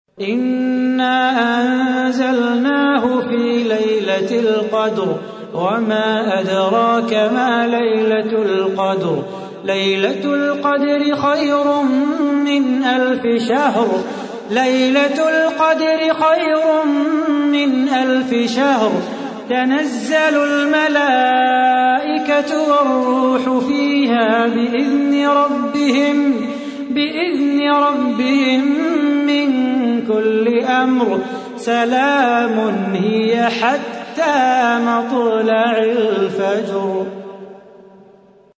تحميل : 97. سورة القدر / القارئ صلاح بو خاطر / القرآن الكريم / موقع يا حسين